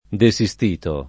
desistere [ de S&S tere ]